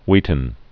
(wētn, hwētn)